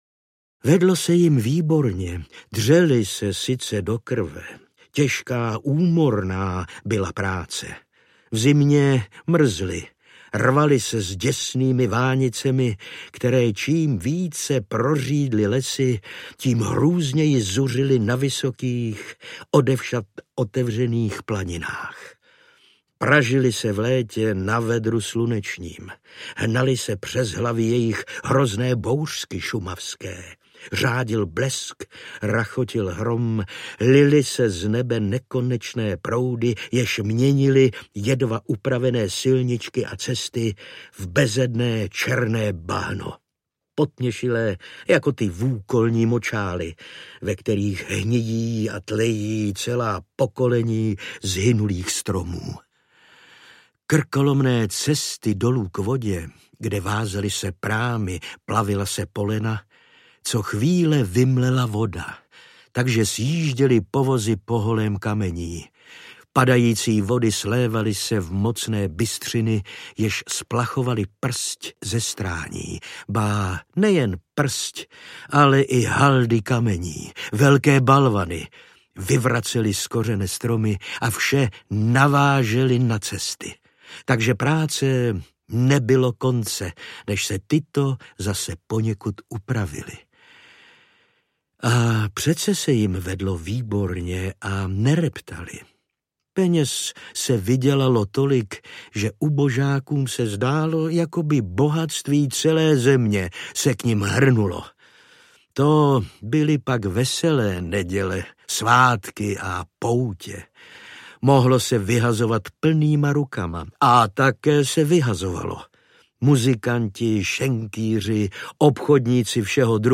V ráji šumavském audiokniha
Ukázka z knihy